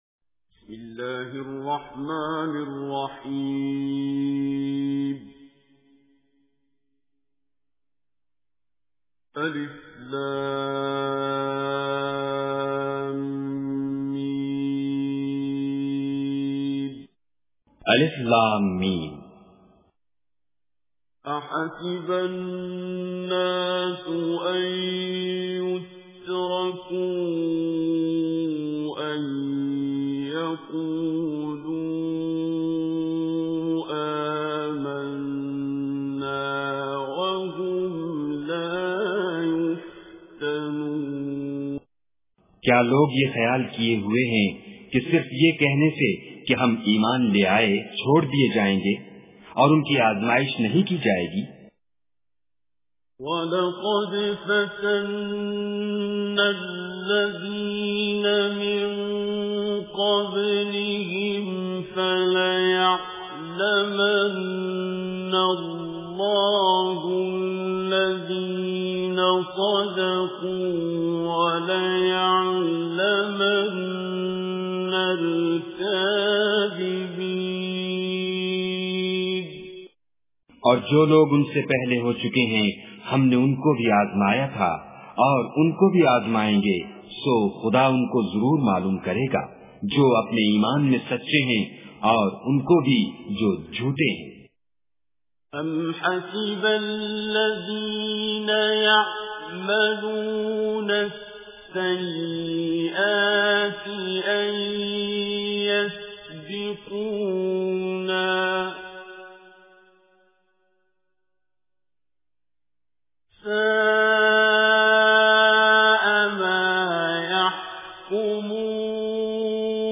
Surah Al-Ankabut Recitation with Urdu Translation
Listen online and download beautiful Quran Recitation / Tilawat of Surah Al Ankabut in the voice of Qari Abdul Basit As Samad.